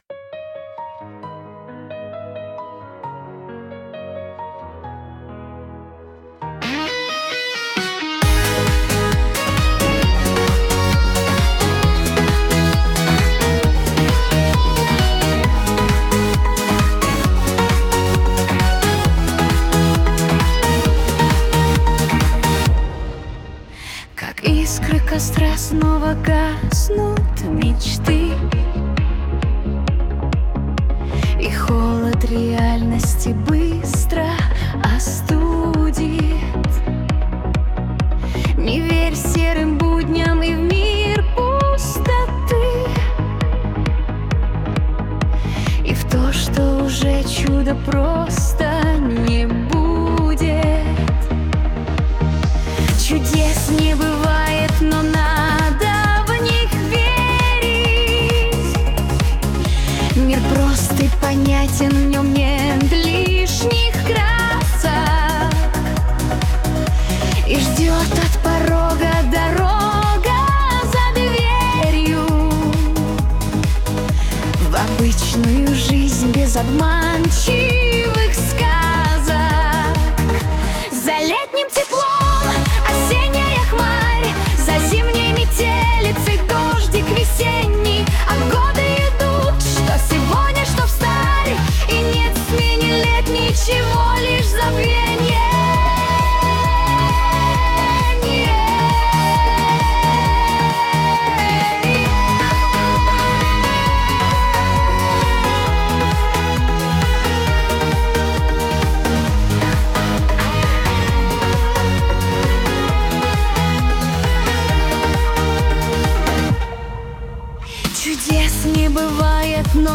С музыкальным сопровождением